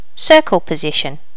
Additional sounds, some clean up but still need to do click removal on the majority.
circle position.wav